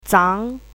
chinese-voice - 汉字语音库
zhan2.mp3